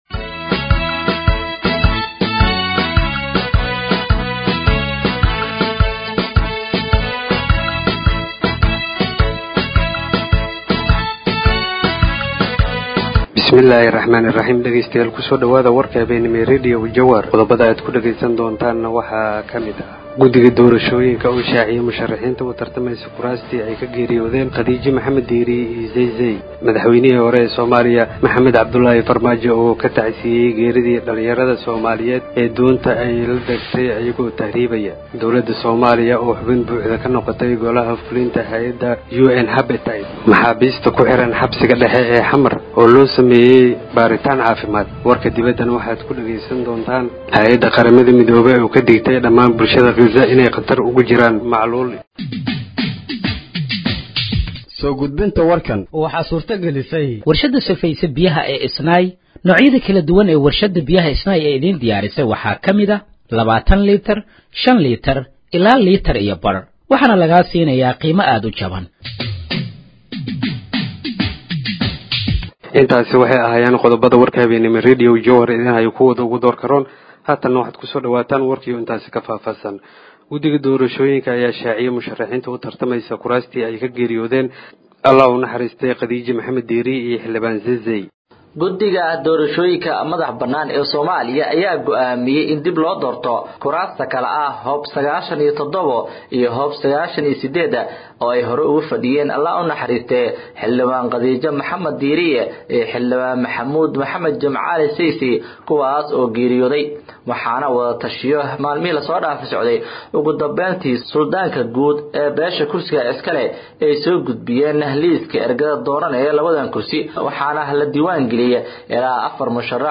Dhageeyso Warka Habeenimo ee Radiojowhar 30/05/2025